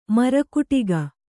♪ mara kuṭiga